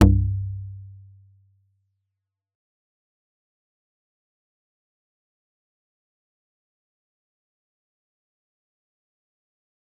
G_Kalimba-C2-f.wav